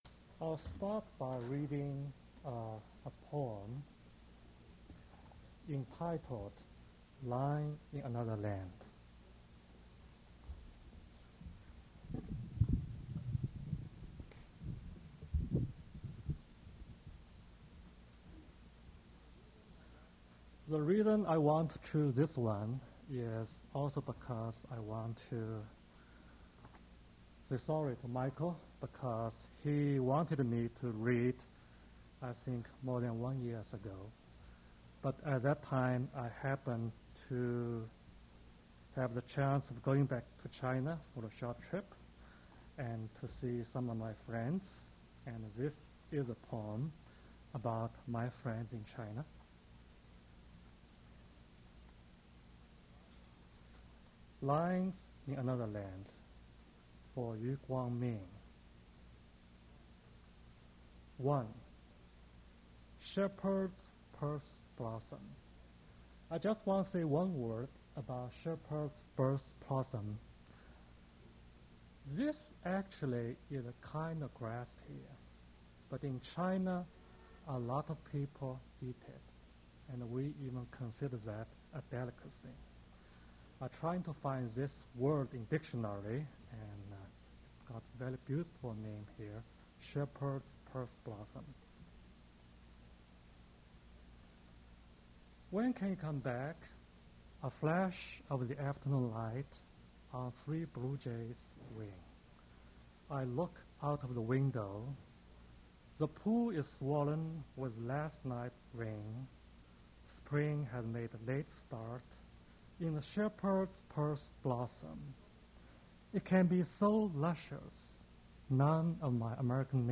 Poetry reading featuring Qiu Xiaolong
Attributes Attribute Name Values Description Qiu Xiaolong reading his poetry at Duff's Restaurant.
mp3 edited access file was created from unedited access file which was sourced from preservation WAV file that was generated from original audio cassette.
recording starts with poet reading